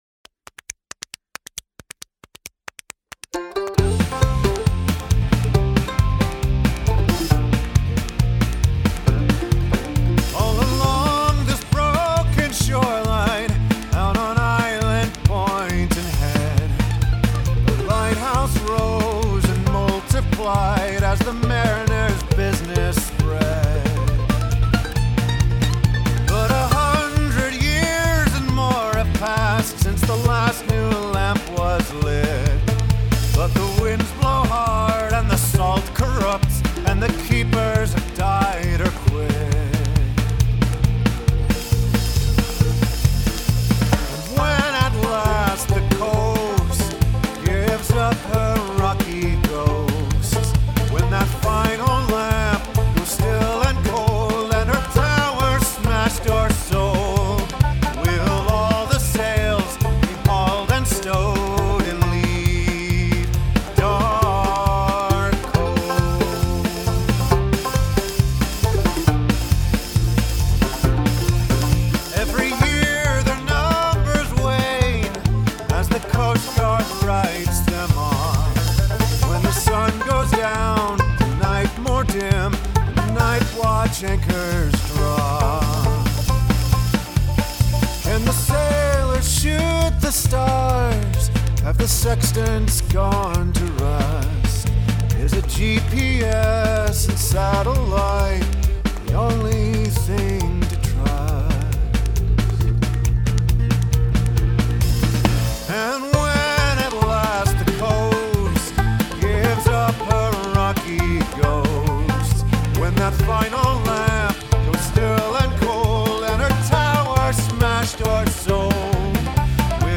Make use of handclaps and snaps
I’m liking the mando a lot. The bass is solid.
The distorted guitar should be a lot higher in the mix.